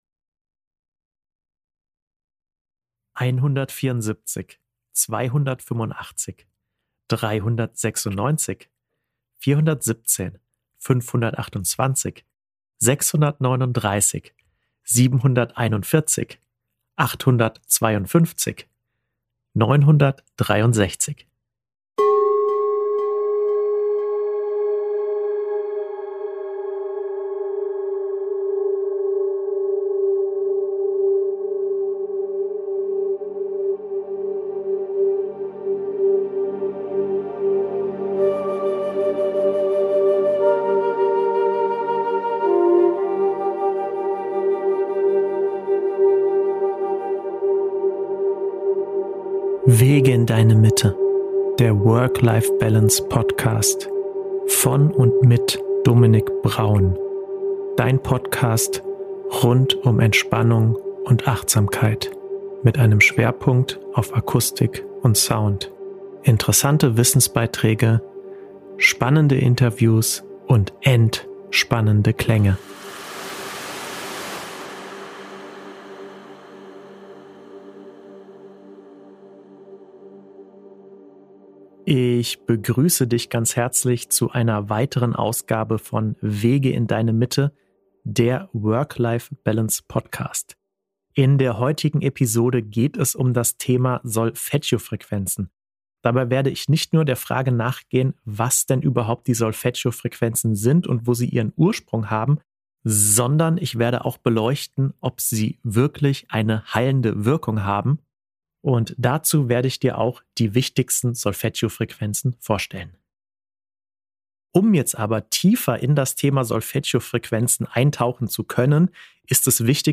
Und vor allem: Haben die Solfeggio-Frequenzen wirklich eine heilende Wirkung? Erfahre die Antworten auf all diese Fragen in dieser Podcast-Episode, die zudem viele Klangbeispiele bereithält.